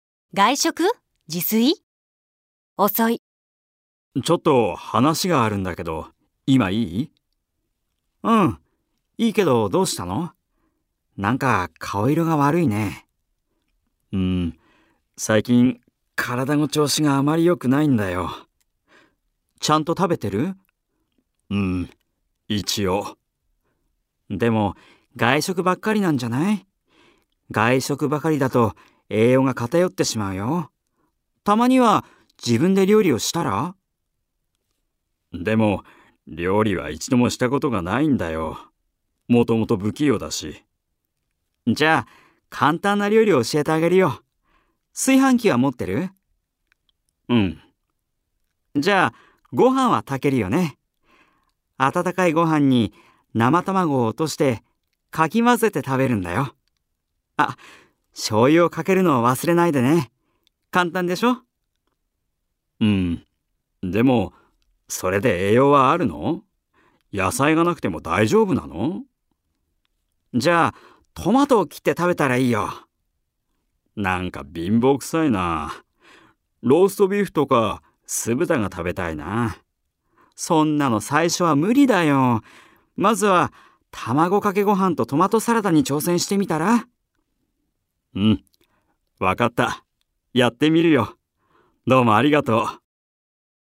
新版では、「復習しよう！」「練習しよう！」の解答例と、「機能別上級会話表現」を別冊として付けたほか、「復習しよう！」の会話文の音声を収録。